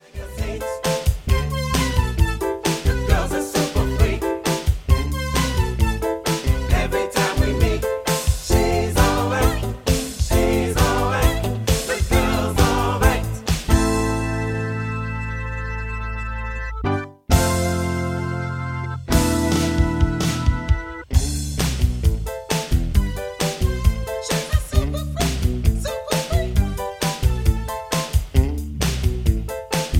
Am
MPEG 1 Layer 3 (Stereo)
Backing track Karaoke
Pop, Disco, 1980s